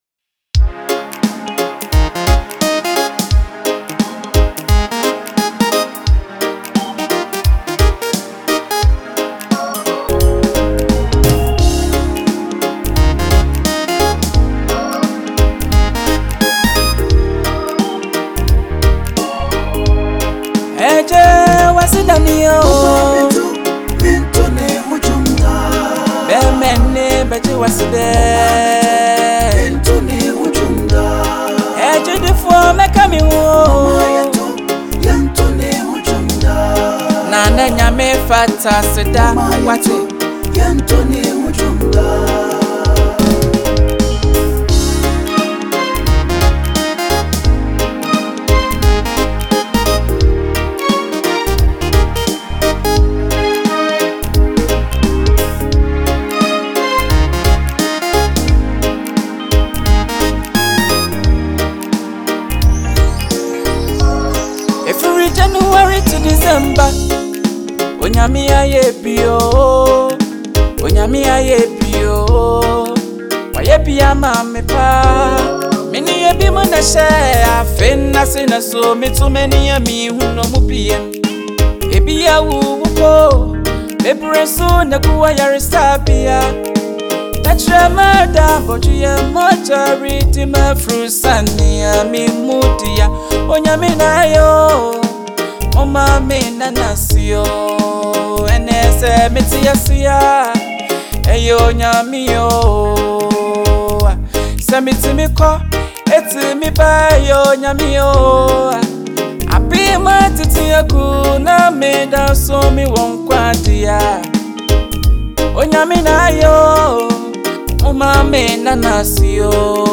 Listen to the new tune from Gospel Musician